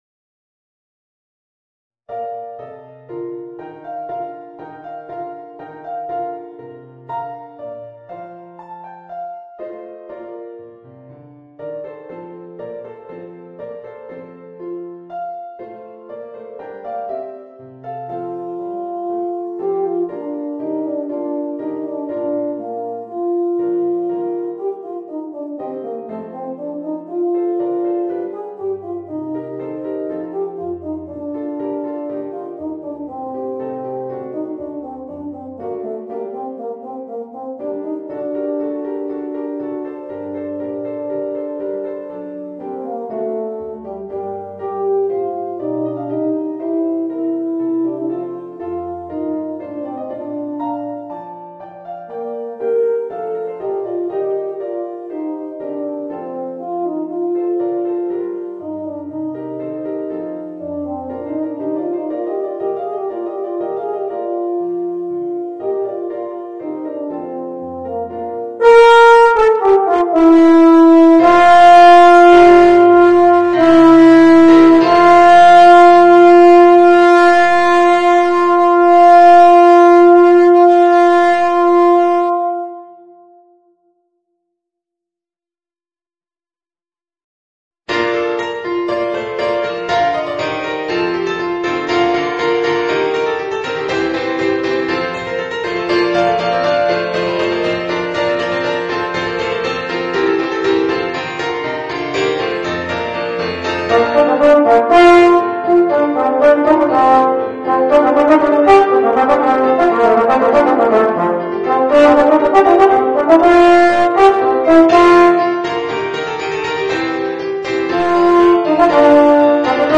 Voicing: Euphonium and Organ